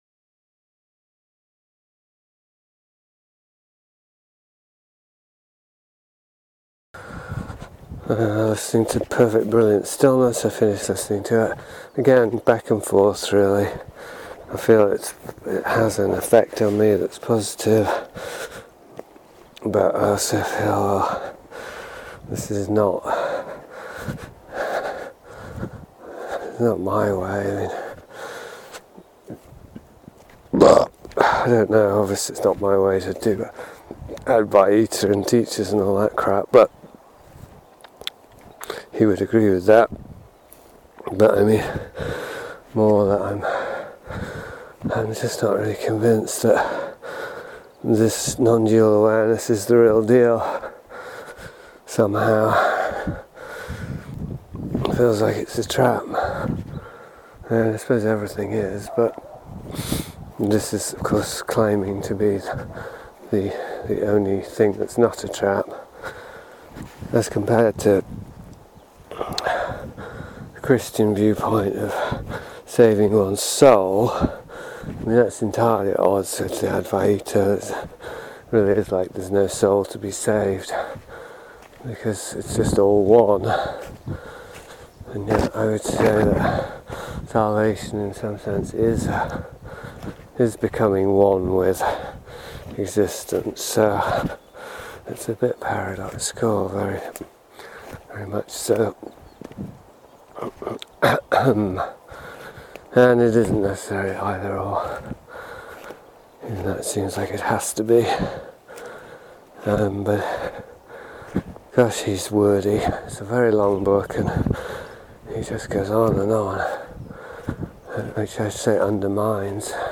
Excuse the panting